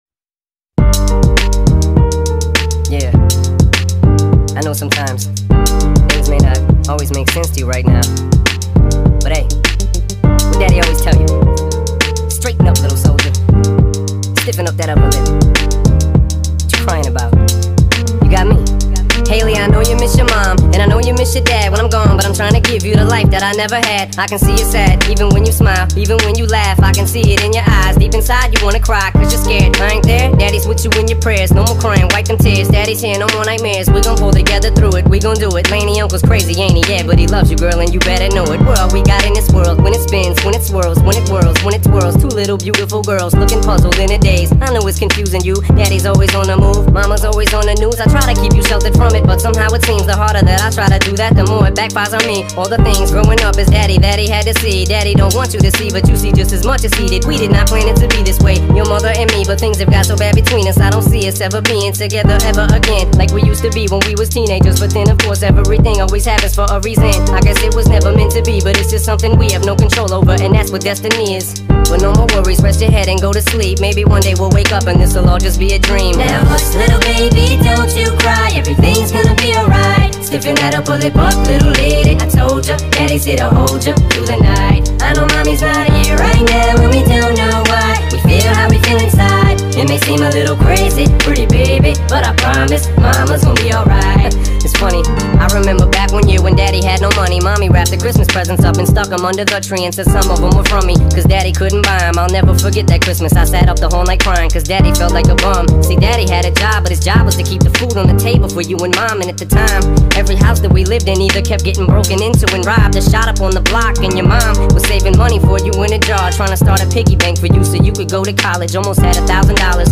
sped up remix